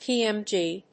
音節P.M.G.